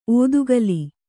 ♪ ōdugali